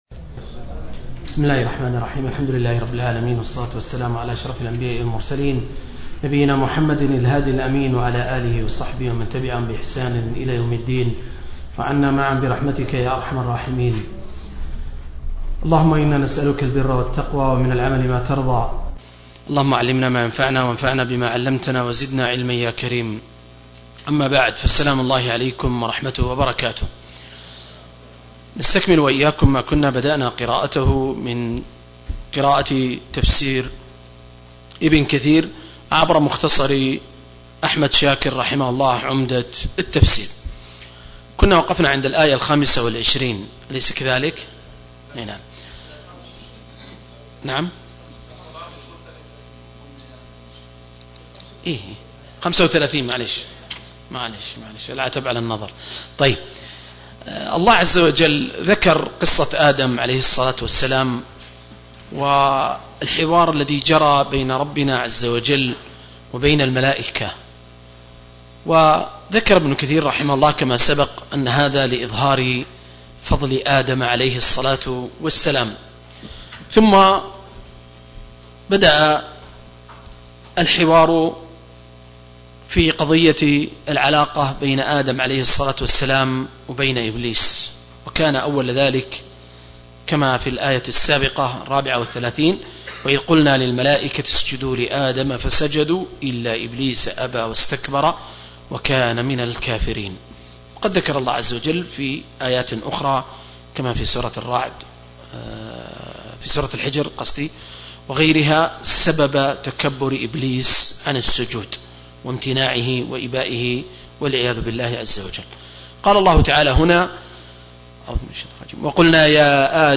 014- عمدة التفسير عن الحافظ ابن كثير – قراءة وتعليق – تفسير سورة البقرة (الآيات 35-43)